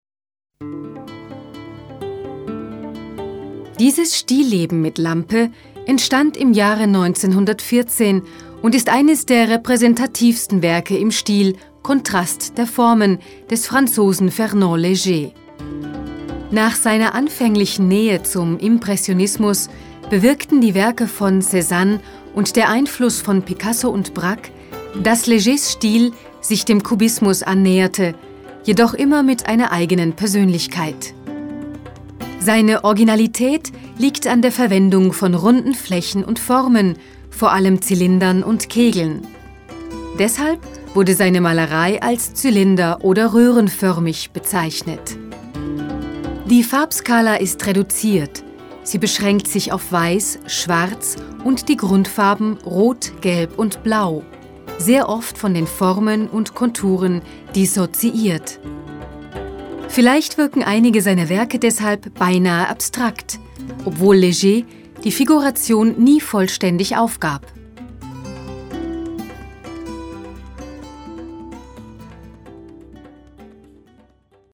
Voice Samples
Museum guide Museo Reina Sofia